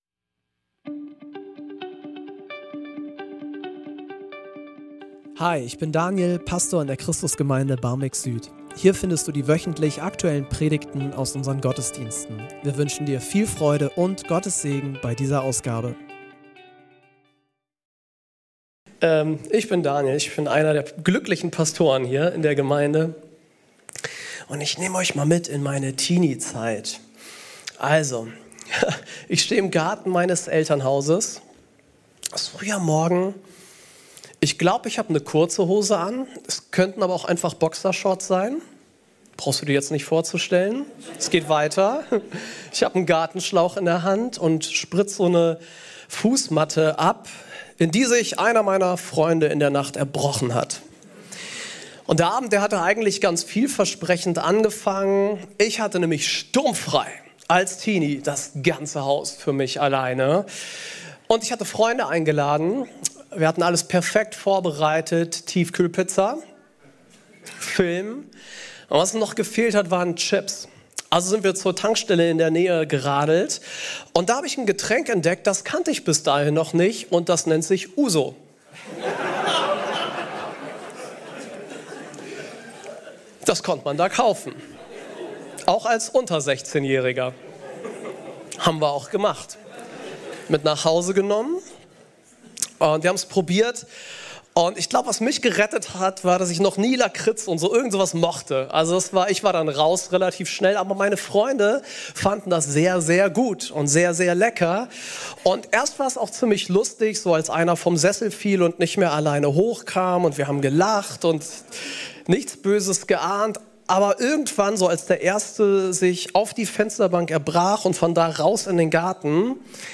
Warum wir uns für Klimaschutz einsetzen ~ Christus Gemeinde Barmbek-Süd Podcast